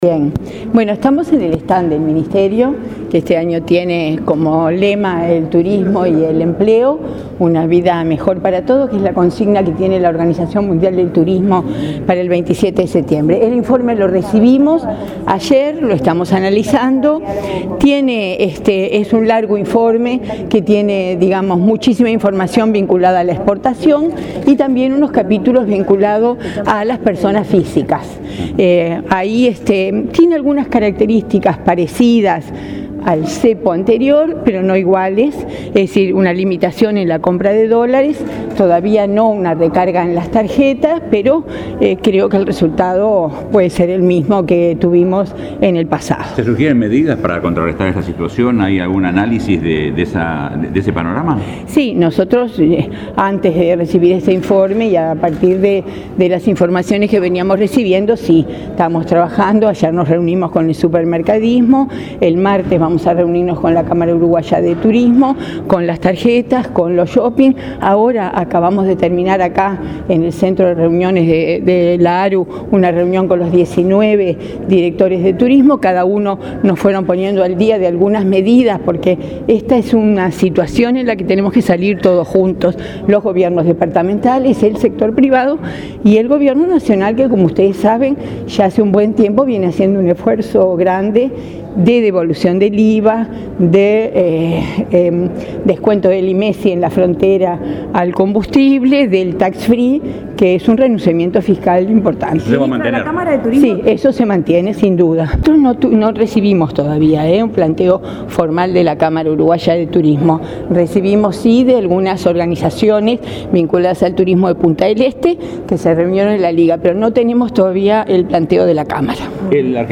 La ministra Liliam Kechichian dijo que se analiza con el sector privado la situación argentina. La jerarca inauguró este miércoles el stand de la cartera en la Rural del Prado, oportunidad en la que sostuvo que las medidas adoptadas por el Gobierno de devolución del IVA, que implica un renunciamiento fiscal de 35 millones de dólares, deberán complementarse con medidas departamentales y del sector privado.